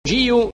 The circumflex accent ( ^ ) indicates that the vowel is long.
• the phoneme /i/, short ( í ): "bibin" /bi'biŋ/ (turkey) or long ( î ): "
gîu" /'ʤi:u/ (lap, round)